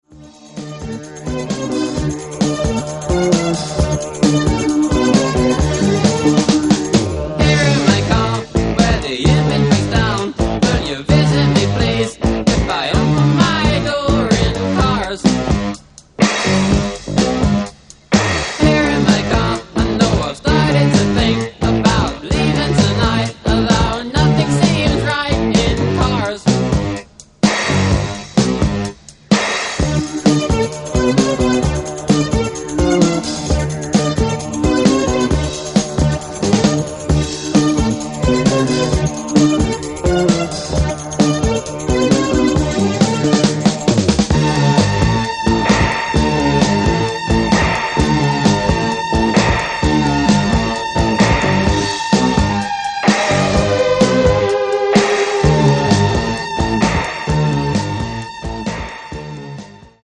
A classic slice of electronic pop